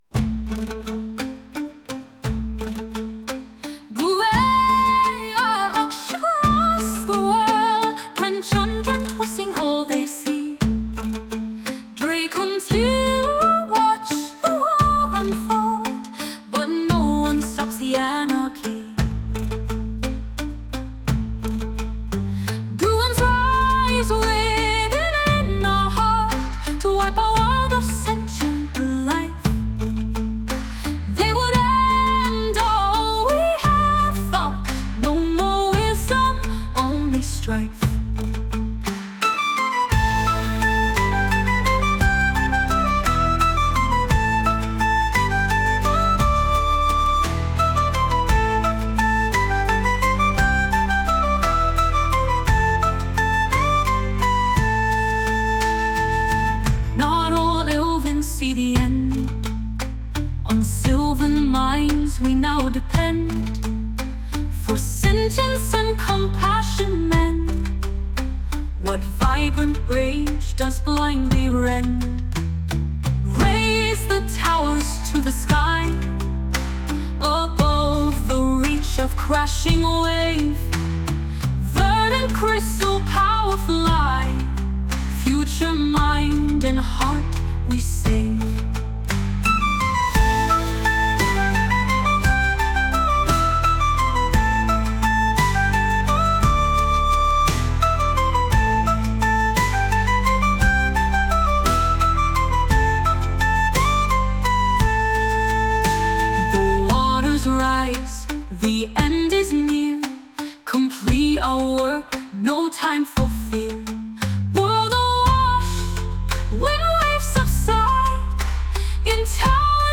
Suddenly, the room fills with the sound of Song...